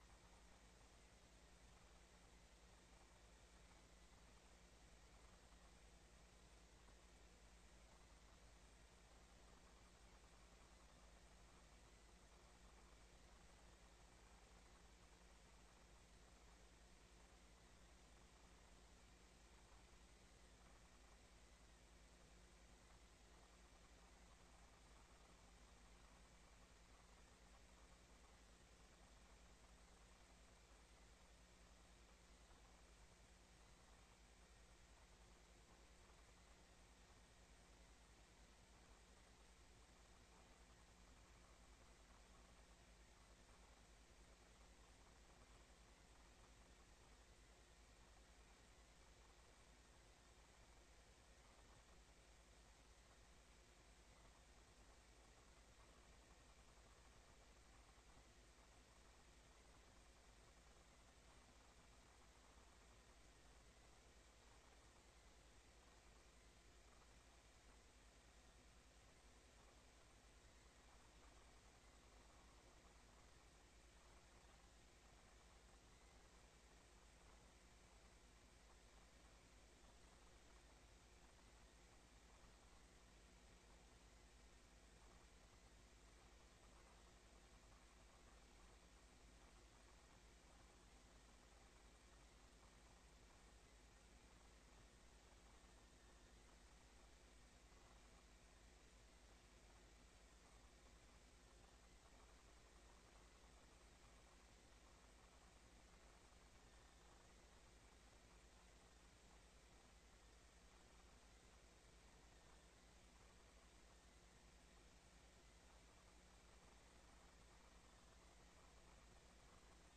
Beeldvormende commissie 09 oktober 2025 20:15:00, Gemeenteraad Hillegom
De voorzitter opent de beeldvormende commissievergadering om 20.15 uur.
Locatie: Raadzaal